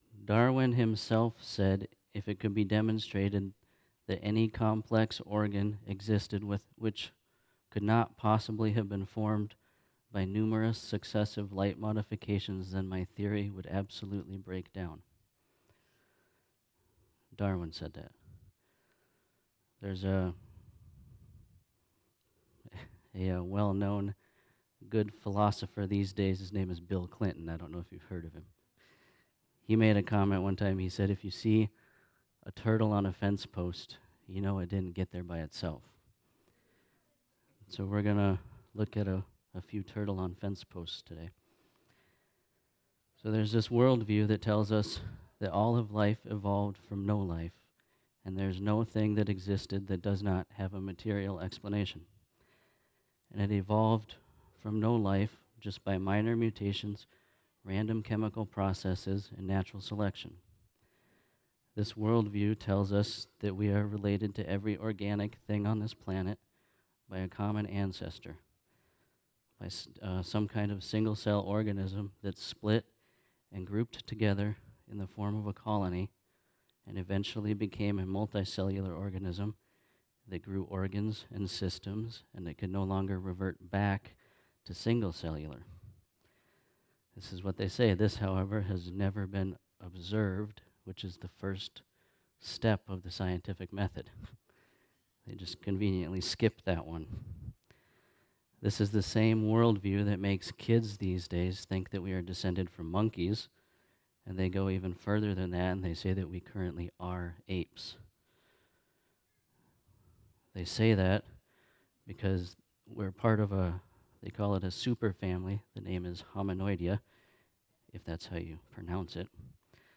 This is a special class-series Friendship church is providing for all who want to not just say they believe in God, but to prove He exists.